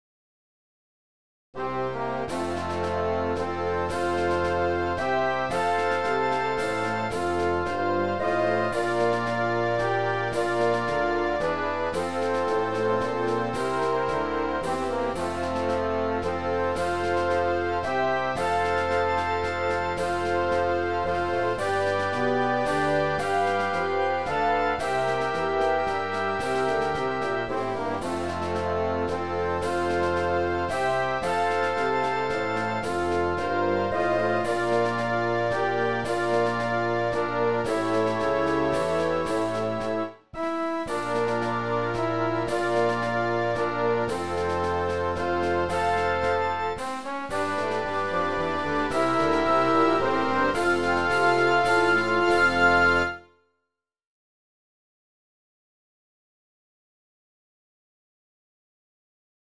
I offered to arrange their Alma Mater (school song) for the school to use at games as they have no band. The song sounds like a cross between "In the Good ole Summertime" and an old hymn named "I Love to Tell the Story."